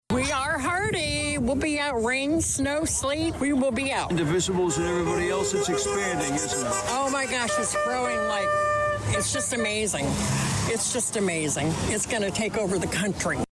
It began at 11 AM, on Main Street in front of VA Illiana Health Care; with a crowd of already of 70, and the numbers kept increasing during Saturday morning’s HANDS OFF rally in Danville; one of many across the country that were put together to protest the actions of Elon Musk and the Trump Administration.